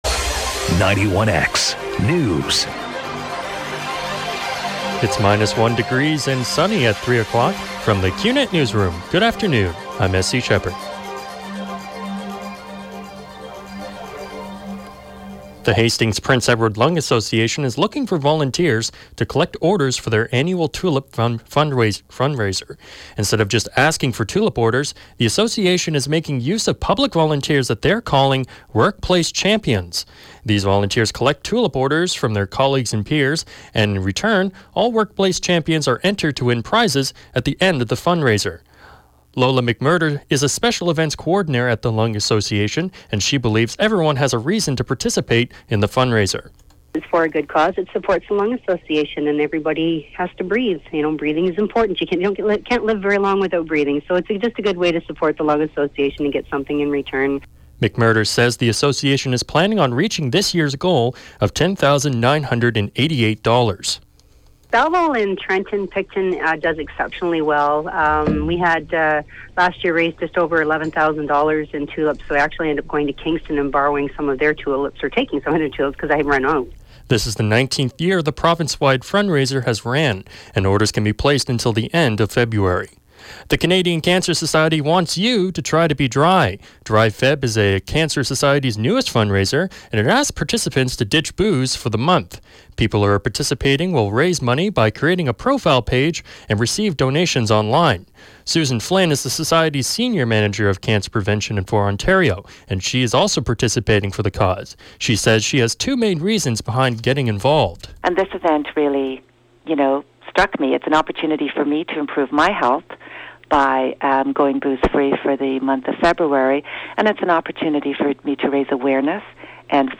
91X newscast – Wednesday, Jan. 27, 2016 – 3 p.m.